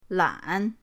lan3.mp3